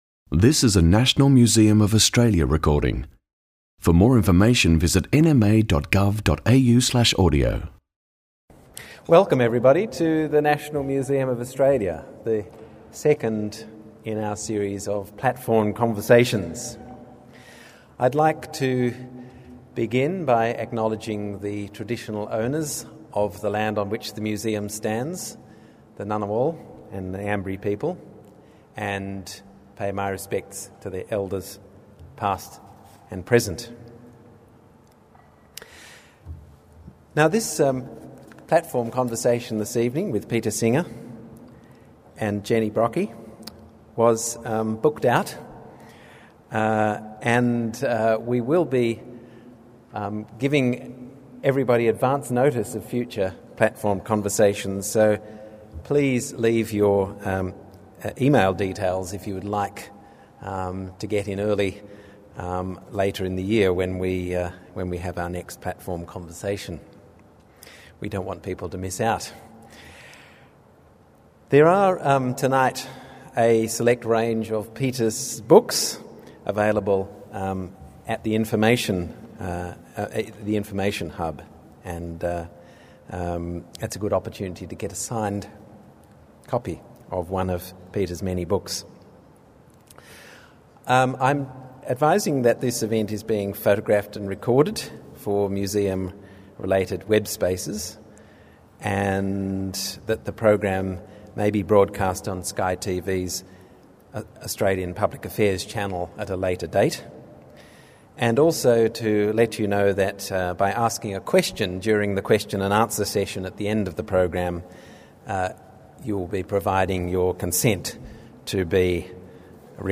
Platform conversations 08 Jul 2011 Constitutional recognition — so what? The inaugural Platform Conversations event was a provocative panel discussion facilitated by David Speers debating whether recognition of Aboriginal and Torres Strait Islanders in the Australian Constitution will make a meaningful difference to Indigenous peoples’ lives.